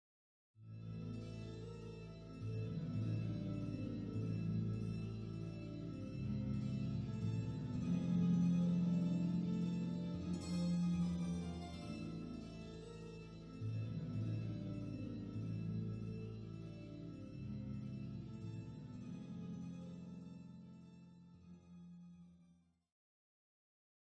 City night (piano, POP
Тема для саундтрека детектива, фрагмент с погоней